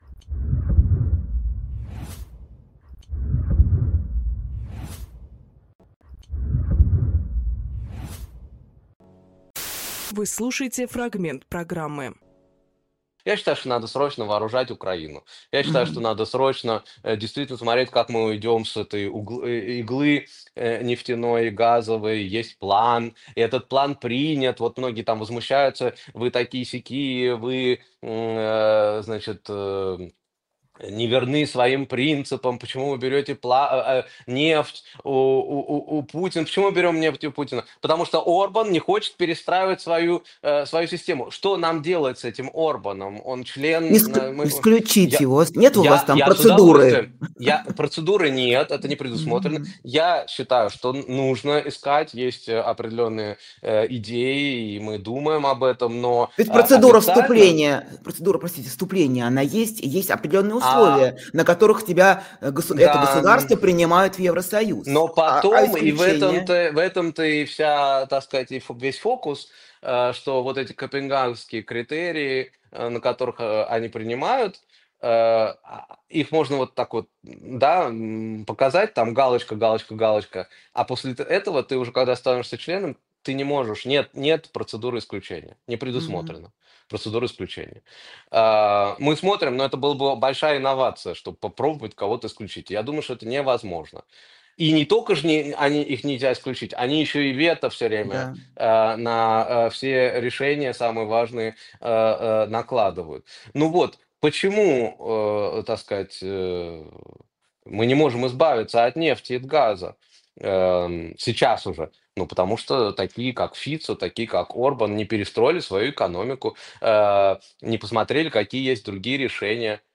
Фрагмент эфира от 10.11.25